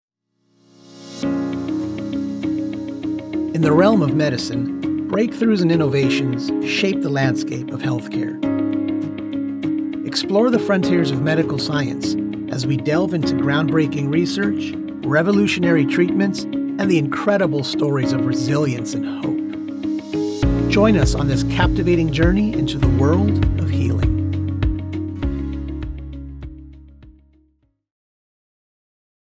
Documentary
My voice quality is clear with a slight amount of "grit" that lends to its uniqueness.